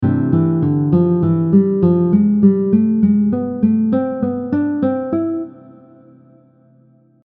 Example 4 using thirds
The only exception where you get an interval of a Major 2nd is between the scale degrees 5 and 6 on measure 2.
Major-6-diminished-scale-example-4.mp3